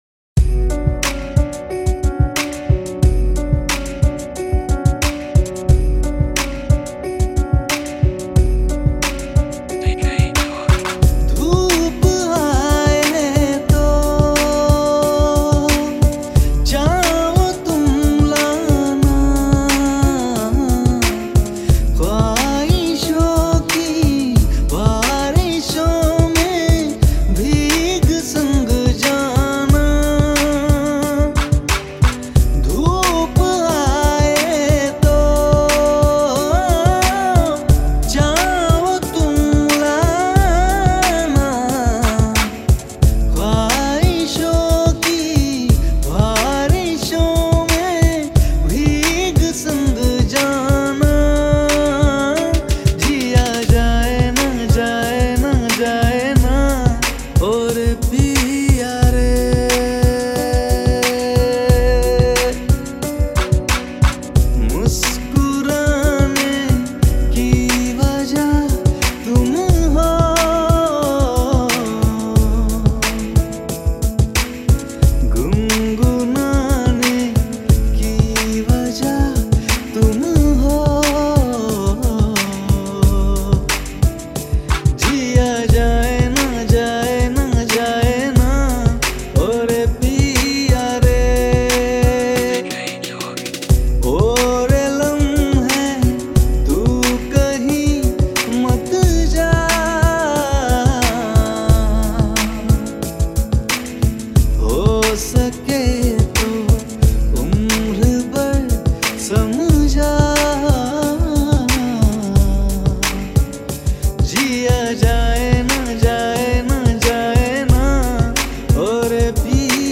Hip Hop Mix
90 Bpm Mp3 Download